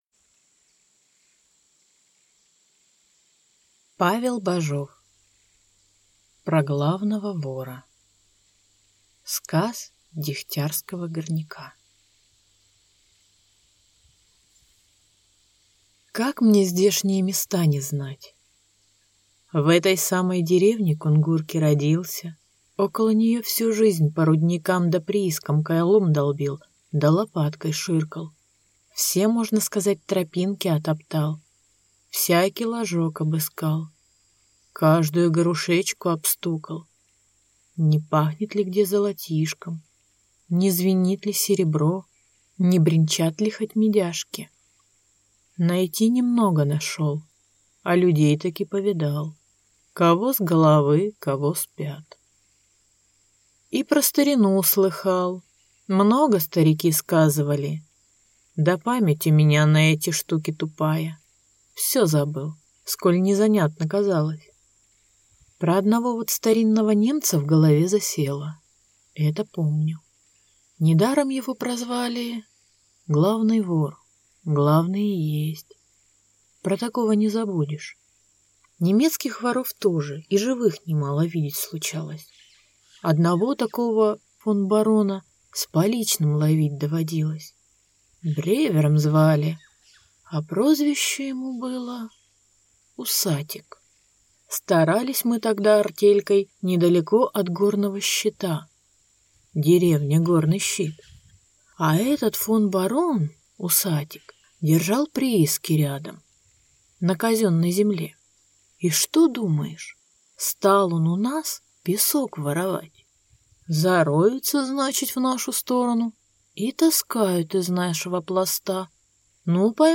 Аудиокнига Про главного вора | Библиотека аудиокниг